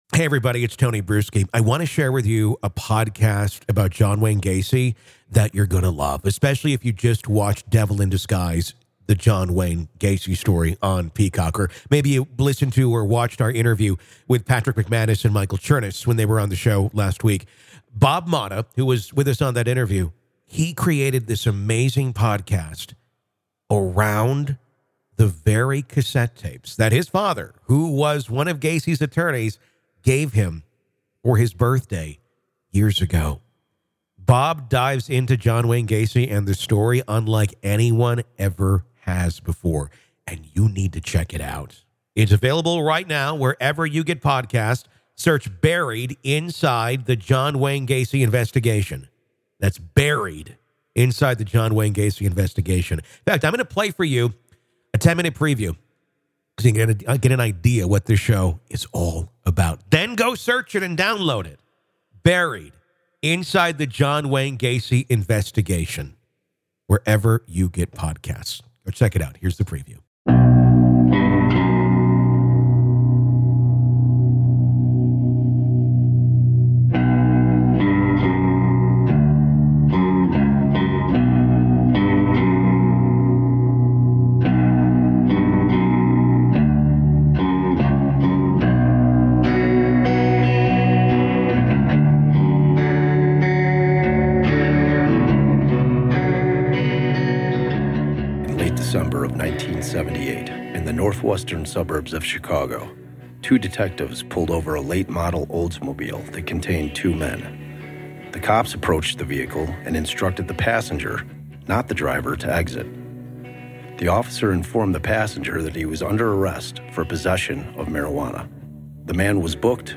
These are the original recordings and behind-the-scenes accounts, brought to life with professional remastering and deeper clarity than ever before.